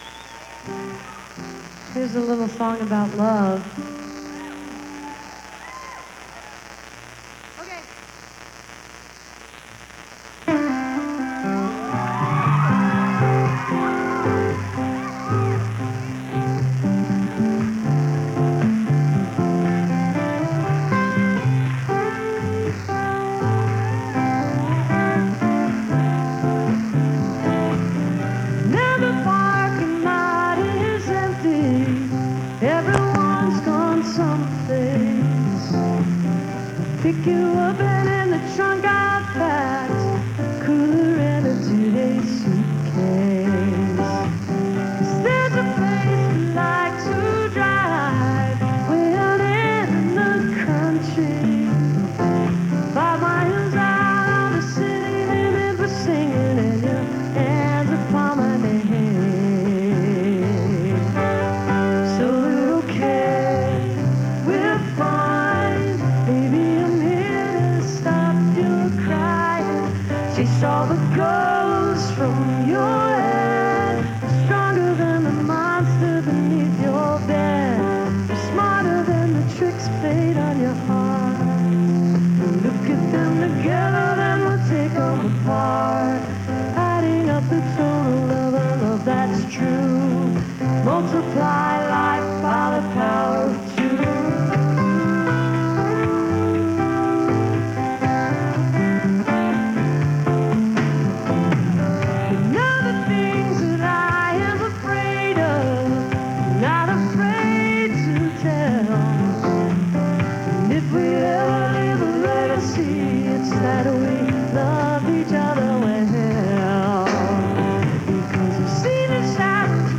(radio broadcast)